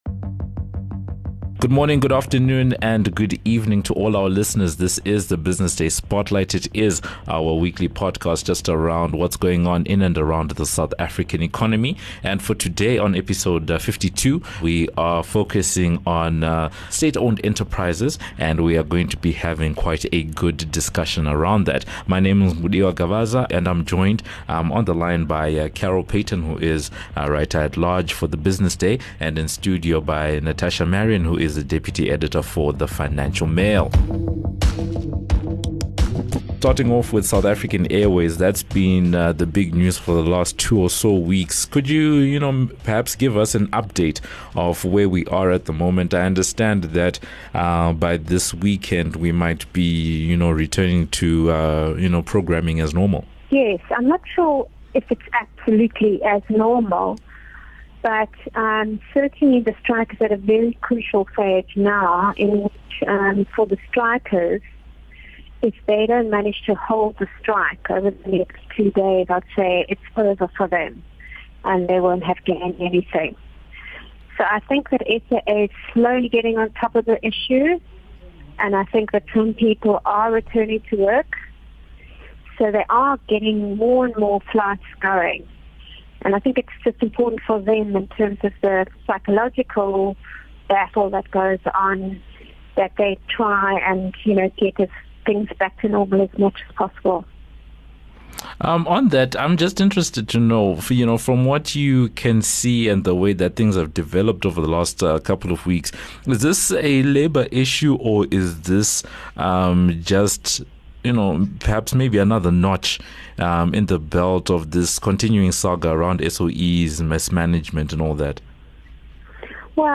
The discussion begins with SAA.